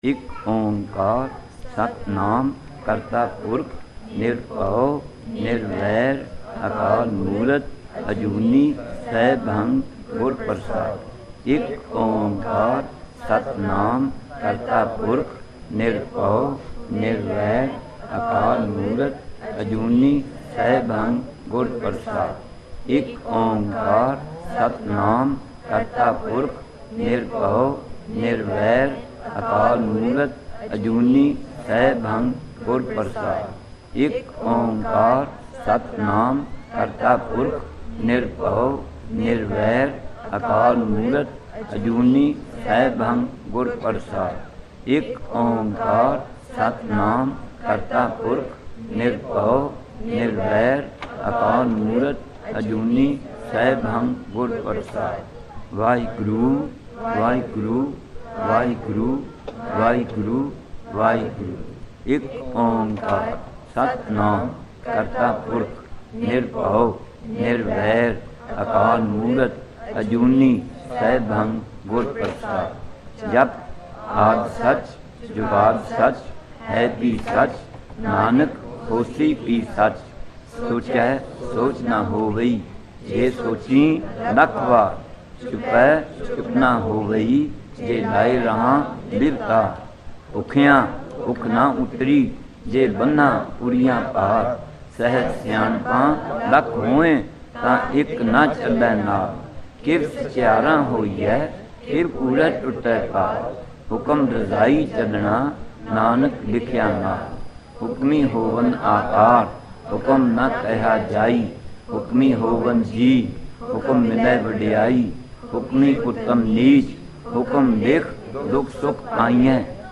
Gurbani Ucharan(Paath Sahib)